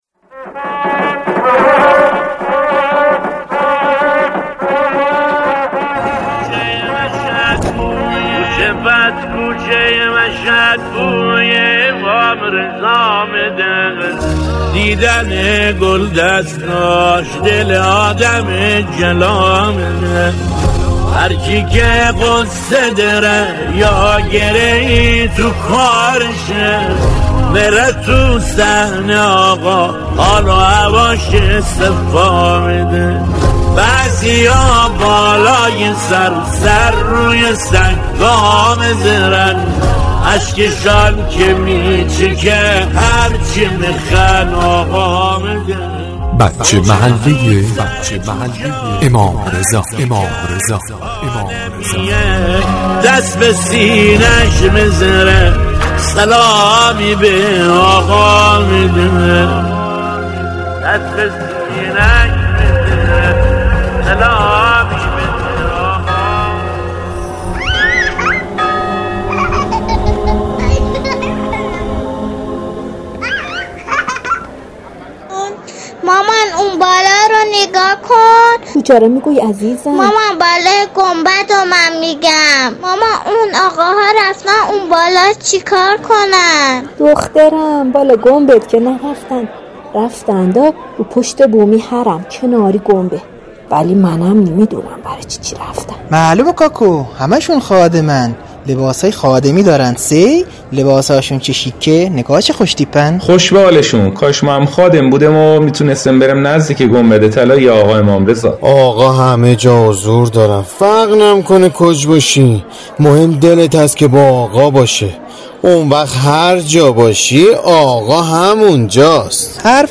نمایش رادیویی